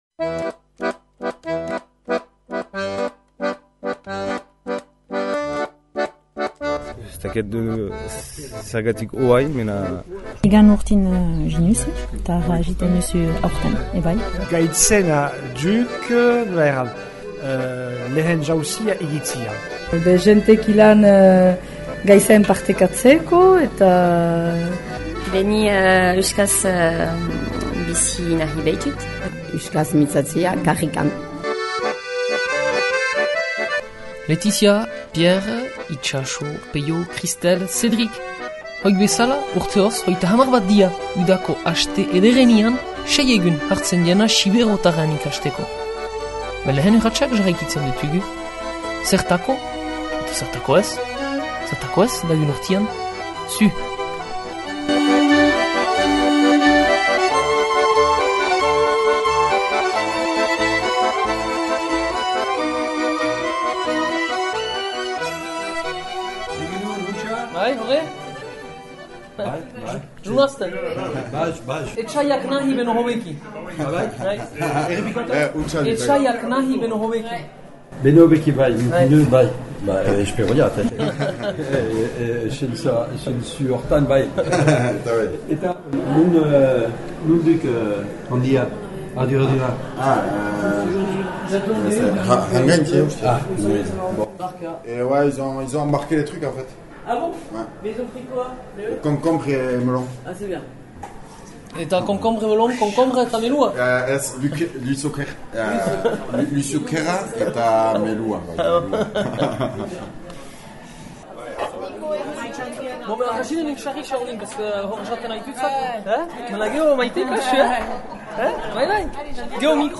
erreportakeikastaldilehena.mp3